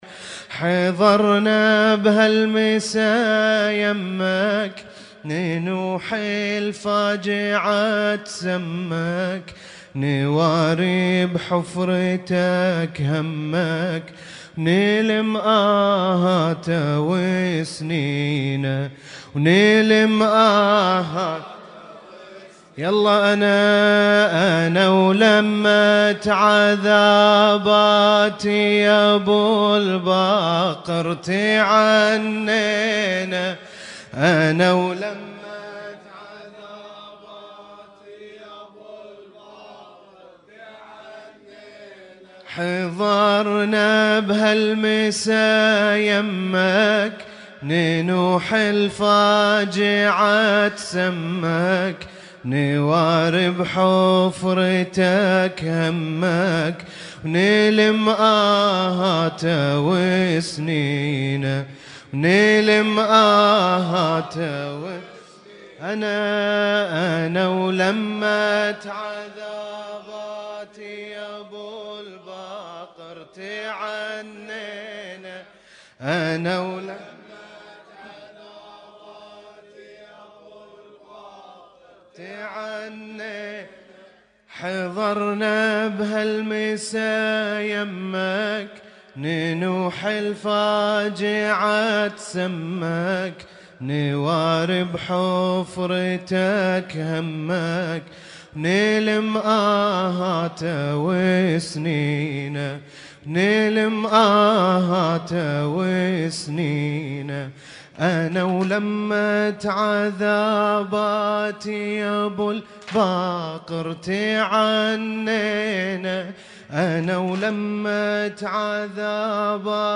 Husainyt Alnoor Rumaithiya Kuwait
مجلس اللطم